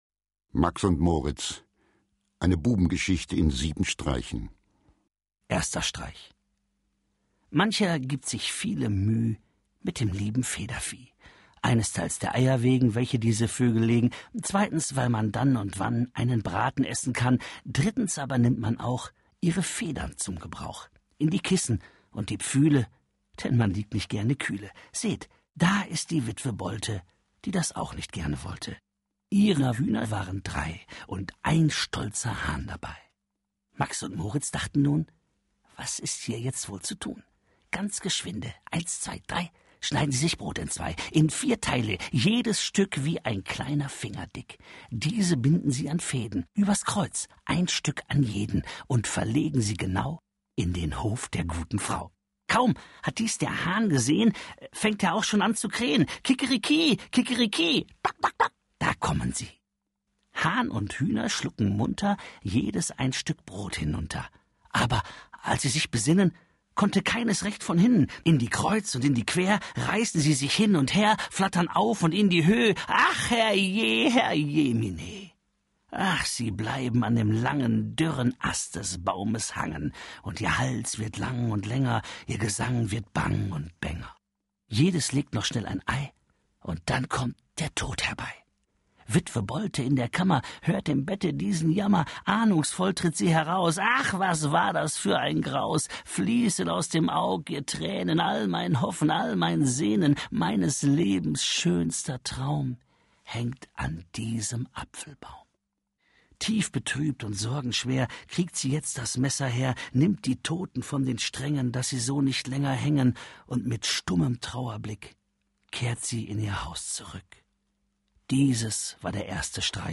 Mit Musik von Götz Alsmann
Götz Alsmann, Otto Sander (Sprecher)
Aus seinem umfangreichen Werk haben Otto Sander und Götz Alsmann eine CD mit ihren Lieblingstexten zusammengestellt und exklusiv für tacheles! eingelesen.